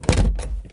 door-open-2.ogg